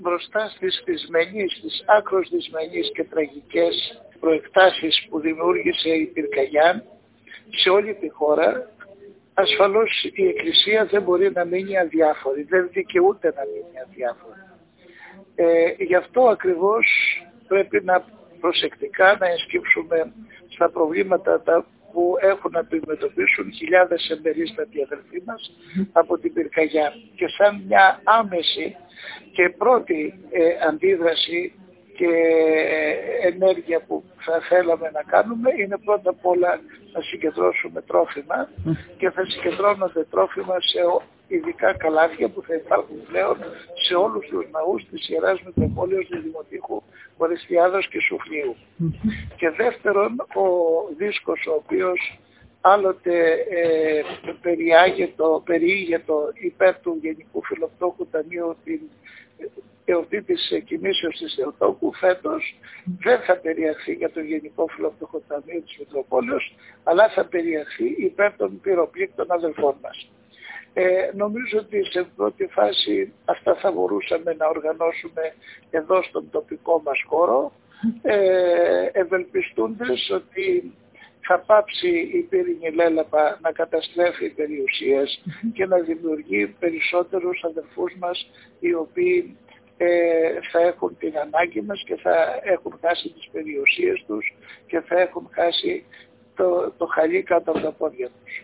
«Η Εκκλησία δεν δικαιούται να μένει αδιάφορη» τόνισε σε δηλώσεις του στη ΕΡΤ Ορεστιάδας ο Μητροπολίτης Δ.Ο.Σ κ. Δαμασκηνός.
Δαμασκηνός-Μητροπολίτης-ΔΟΣ.mp3